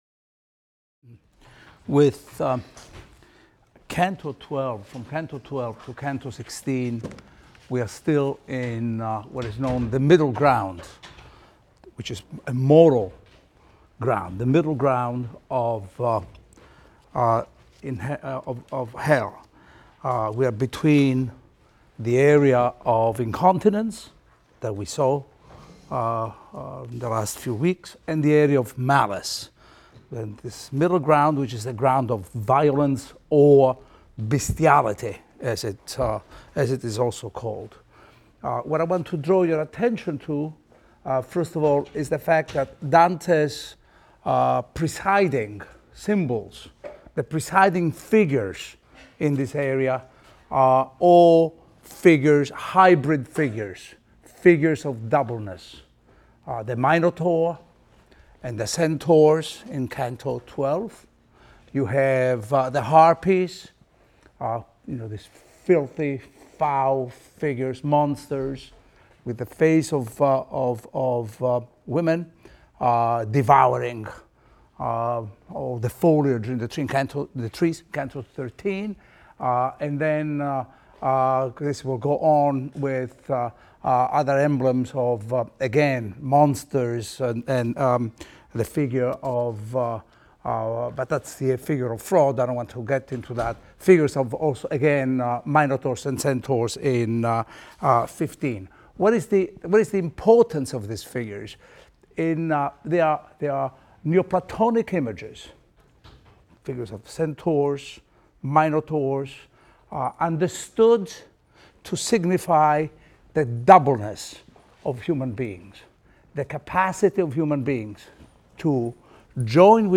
ITAL 310 - Lecture 6 - Inferno XII, XIII, XV, XVI | Open Yale Courses